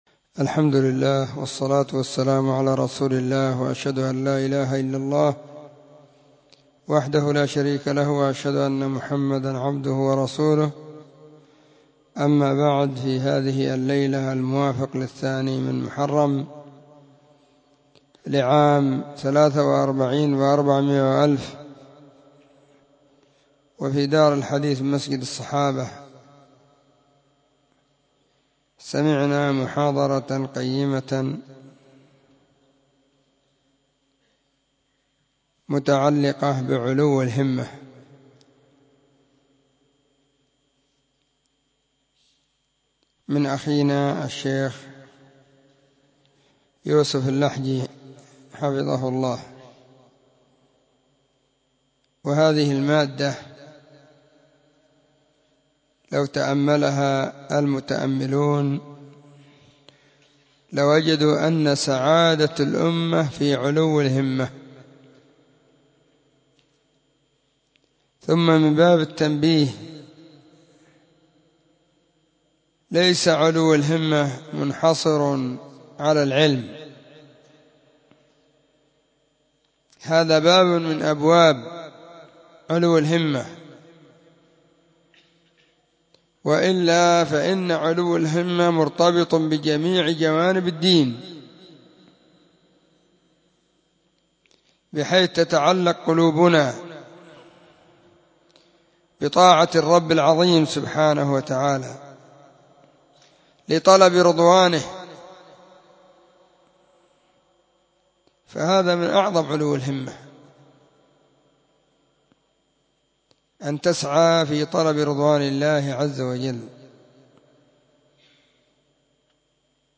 محاضرة
📢 مسجد الصحابة – بالغيضة – المهرة، اليمن حرسها الله،